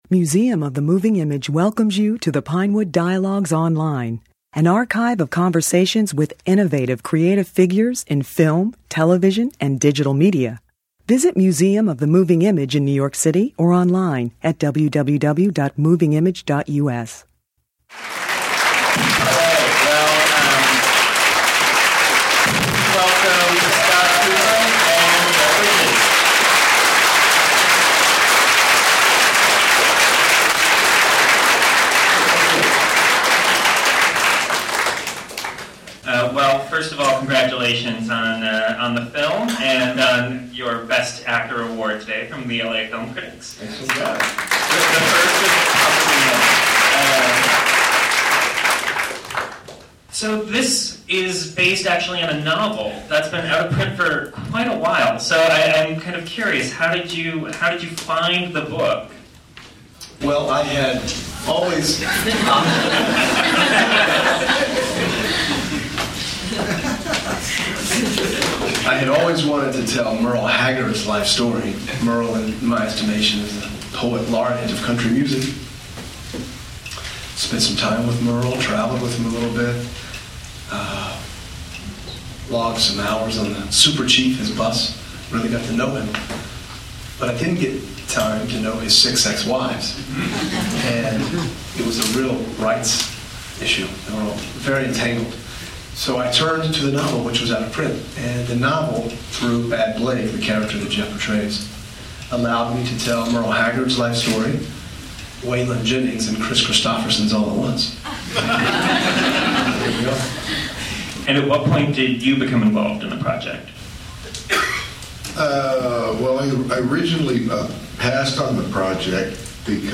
Bridges and Cooper spoke after a special Museum of the Moving Image screening. (Note: the audio quality is poor).